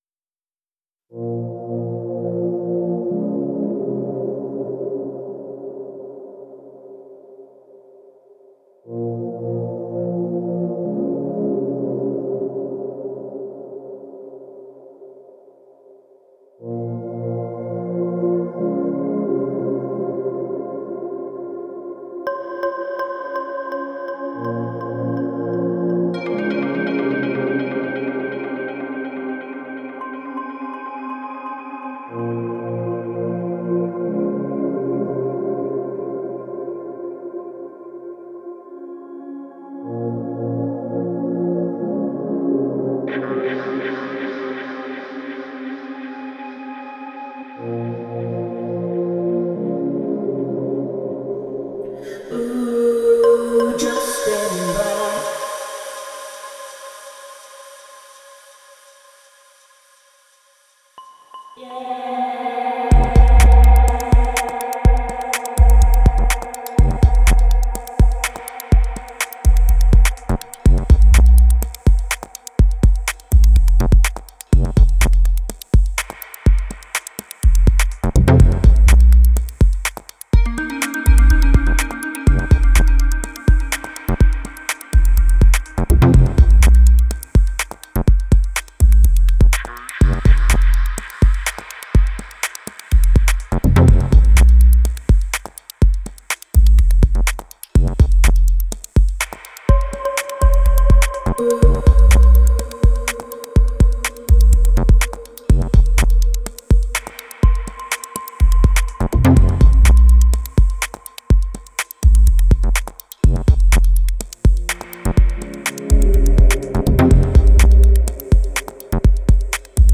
Genre: Downtempo, Dub.